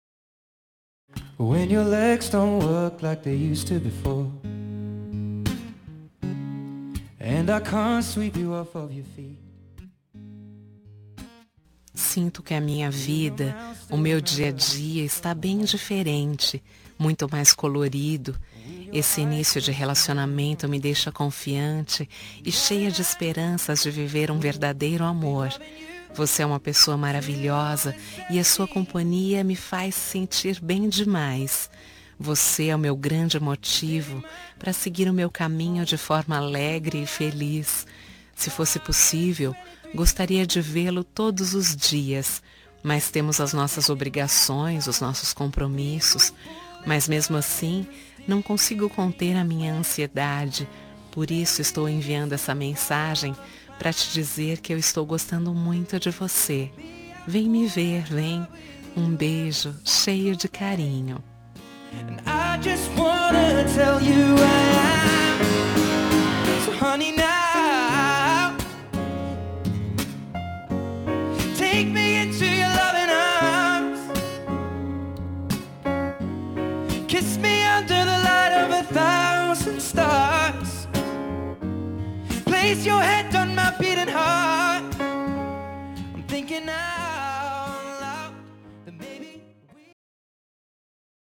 Telemensagem Início de Namoro – Voz Feminina – Cód: 743